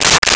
Stapler 01
Stapler 01.wav